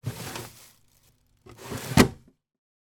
Звуки морозильника
На этой странице собраны звуки работающего морозильника: от монотонного гула до щелчков автоматики.
Звук выдвинули и снова задвинули